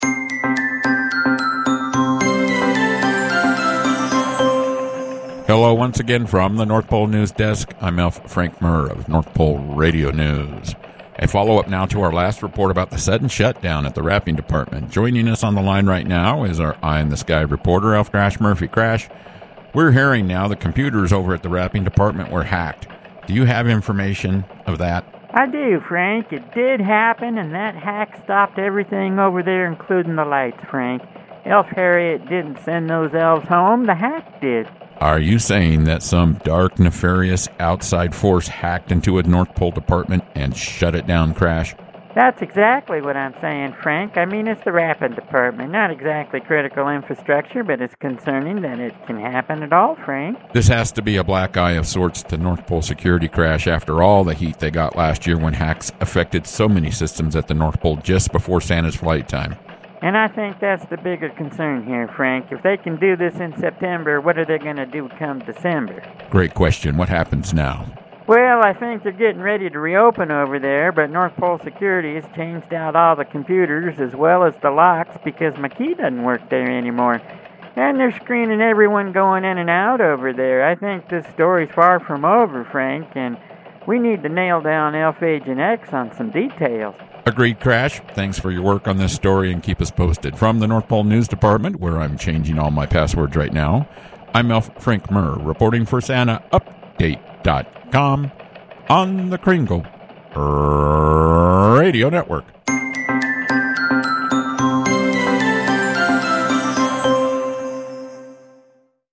North Pole Radio News